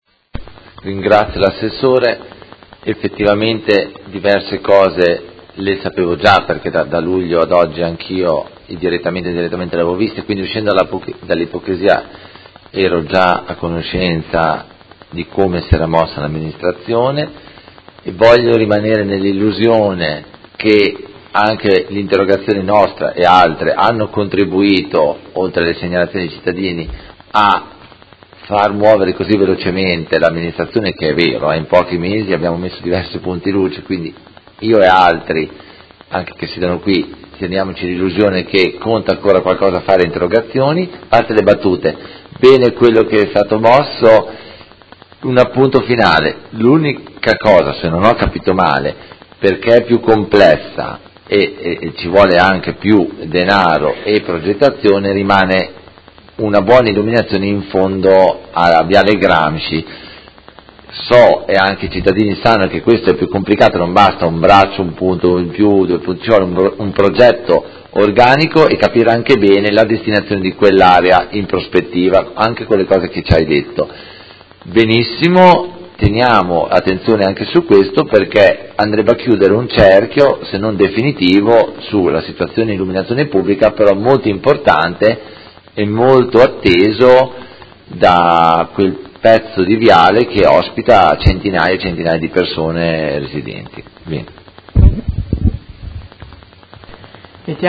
Seduta del 19/10/2017 Replica a risposta Assessore Guerzoni. Interrogazione del Consigliere Carpentieri (PD) avente per oggetto: Viale Gramsci e Parco XXII aprile: nuovi punti luci per migliorare vivibilità e sicurezza